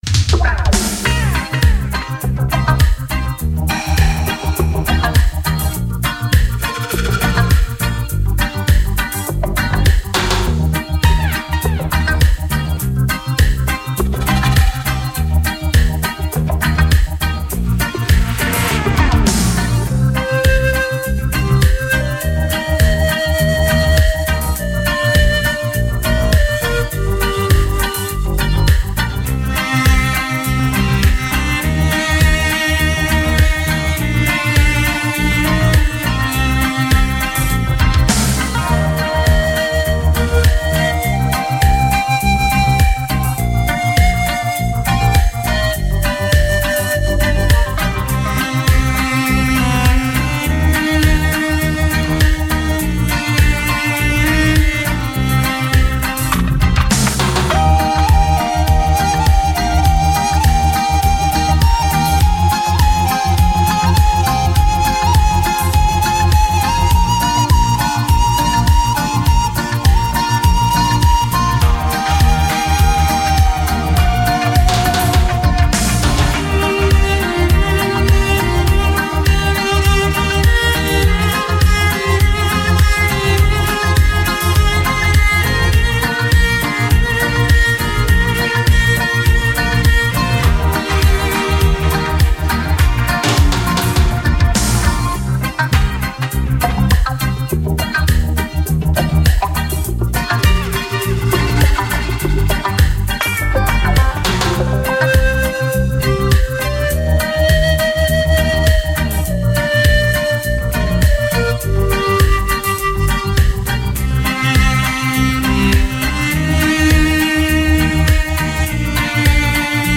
MUSIQUE: LES INSTRUMENTS SUD-AMERICAINS